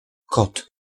The open-mid back rounded vowel, or low-mid back rounded vowel,[1] is a type of vowel sound, used in some spoken languages.
Polish[45] kot
[kɔt̪] 'cat' See Polish phonology